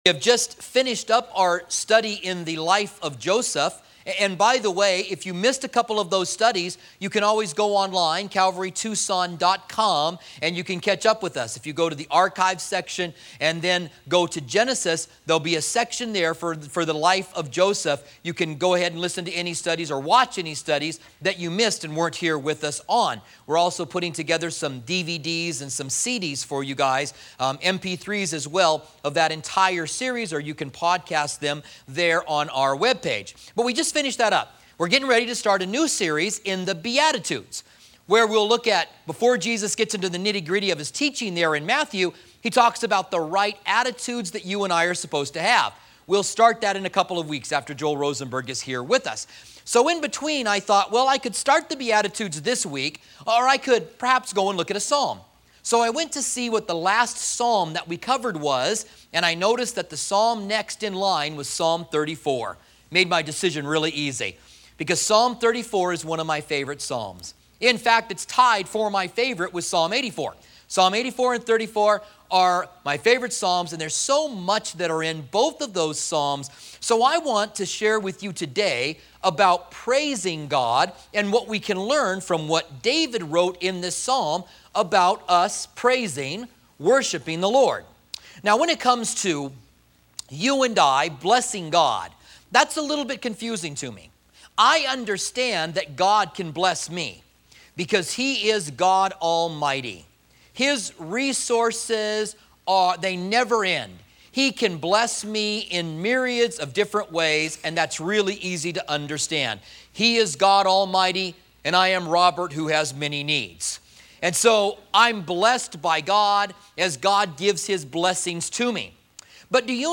Commentary on Psalms